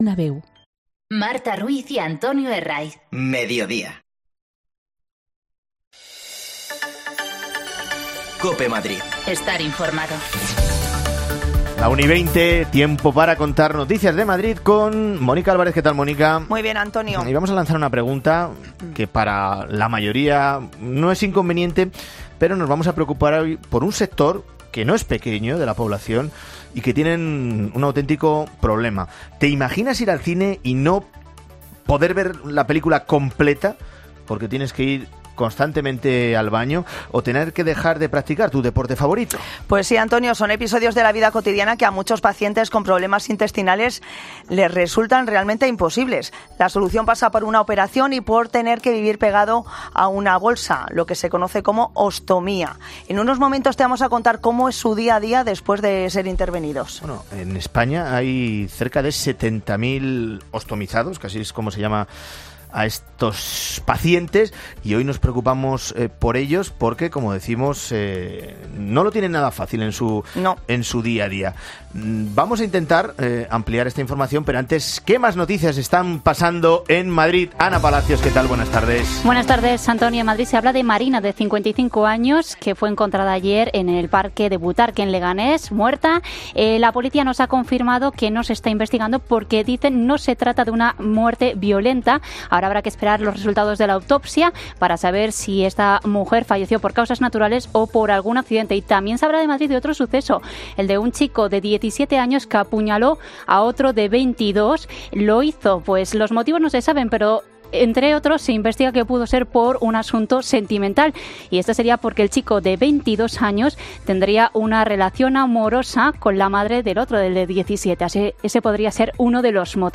El Hospital de Alcalá de Henares expone "Héroes y Heroínas" que recoge cómo le ha cambiado la vida a pacientes ostomizados. Escucha aquí los testimonios.
Las desconexiones locales de Madrid son espacios de 10 minutos de duración que se emiten en COPE , de lunes a viernes.